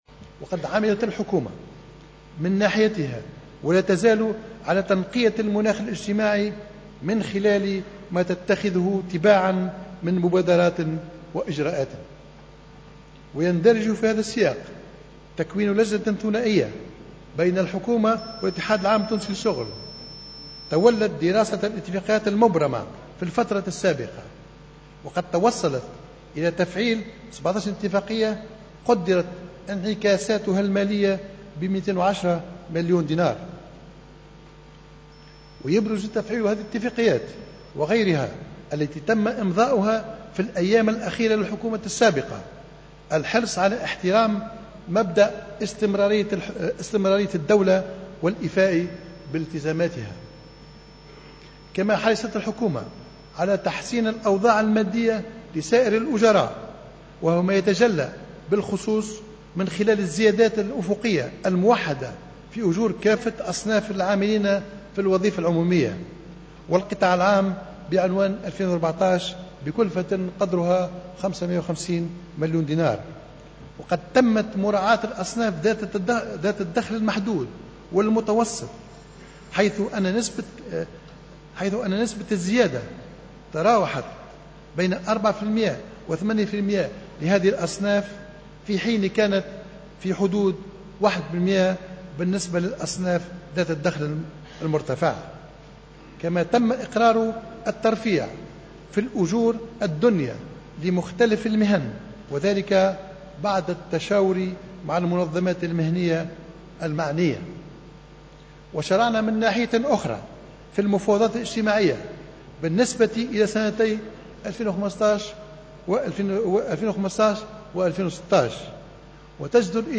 كشف رئيس الحكومة الحبيب الصيد في كلمة ألقاها اليوم الجمعة 5 جوان 2015 في مجلس نواب الشعب أنّه في حال استمرار الصعوبات الماليّة العمومية فإن ذلك سيحول دون الزيادة في الأجور.